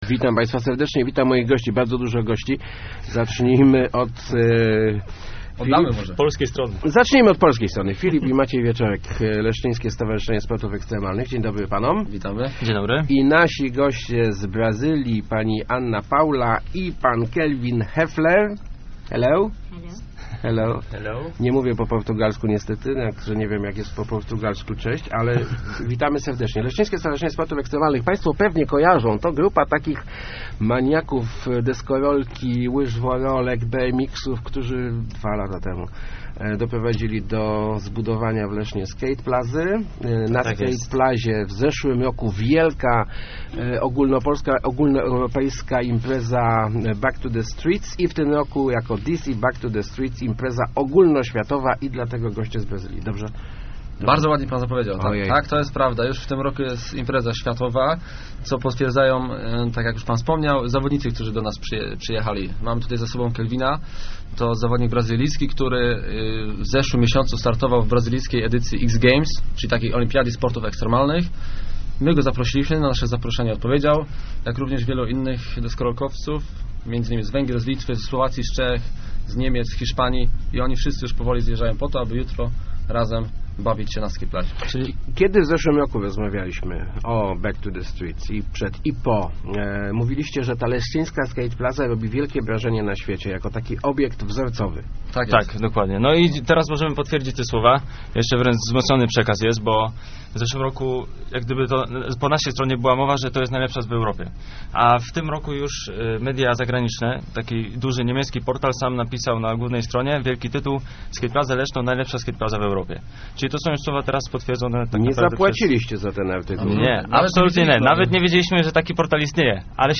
Macie najlepszą skataplazę na świcie - mówił w Rozmowach Elki Brazylijczyk Kelvin Hoefler, uczestnik drugiej edycji DC Back to the Steets - wielkiego turnieju sportów ekstremalnych, który w sobotę i niedzielę odbywał się będzie na Zatorzu.